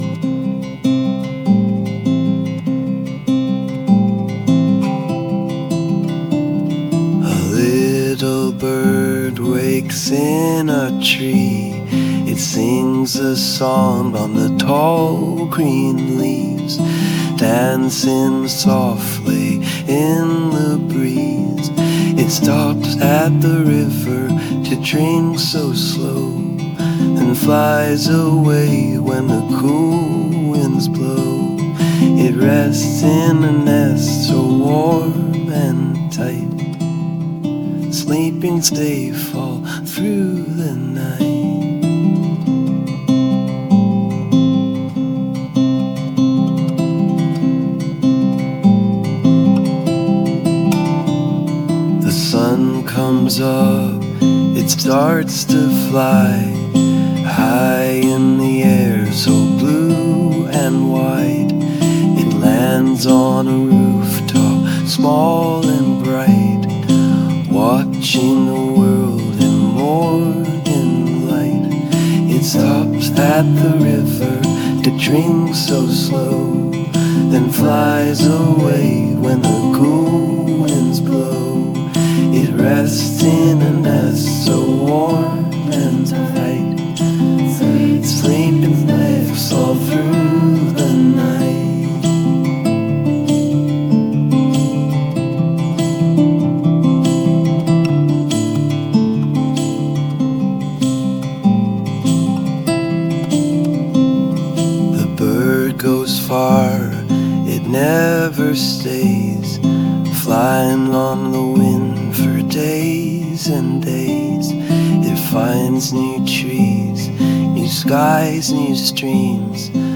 Listening Activity 3 - Song.mp3